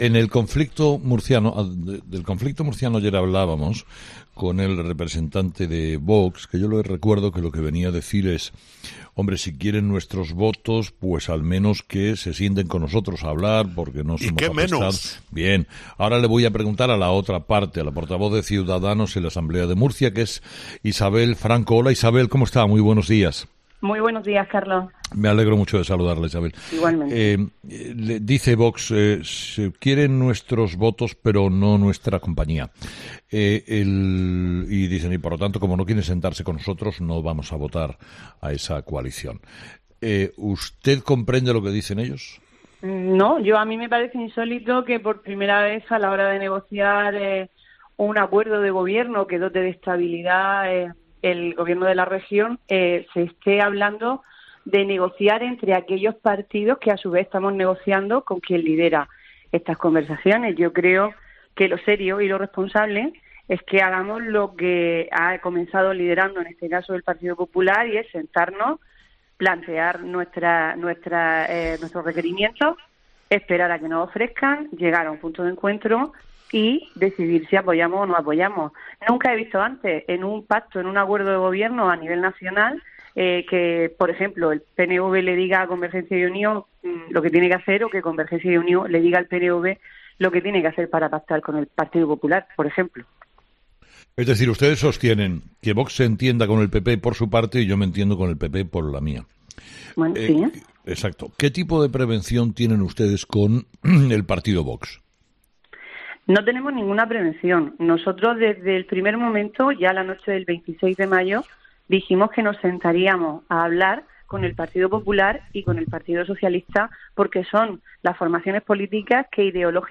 La portavoz de Cs en la Asamblea de Murcia, Isabel Franco en 'Herrera en COPE'